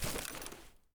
Soundscape Overhaul / gamedata / sounds / material / actor / step / earth2.ogg